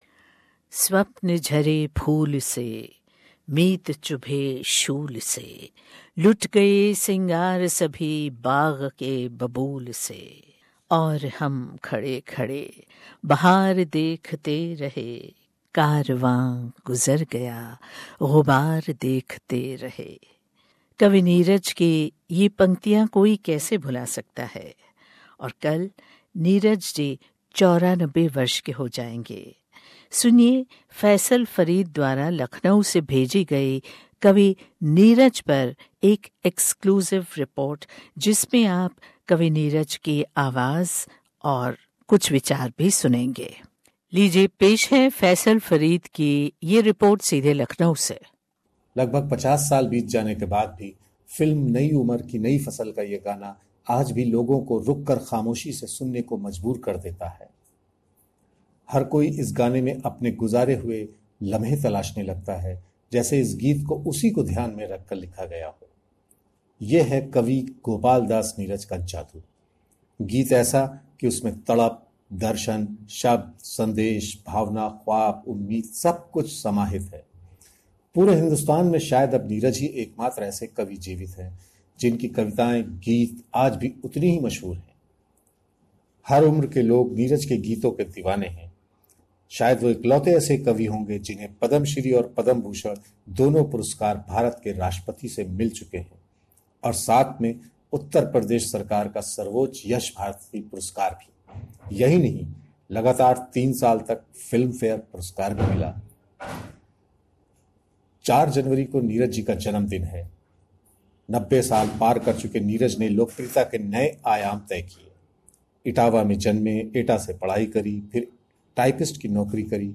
हिंदी कवि और गीतकार गोपालदास नीरज 94 वर्ष के हो गए हैं. इस मौके पर पेश है उनसे एक विशेष बातचीत, उनके अस्पताल से घर लौटने के बाद...